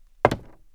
woodFootstep02.wav